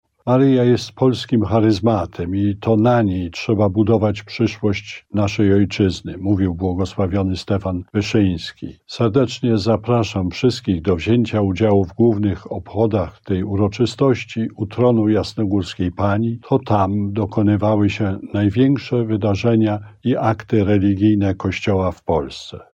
Mówi abp Stanisław Gądecki, przewodniczący Konferencji Episkopatu Polski.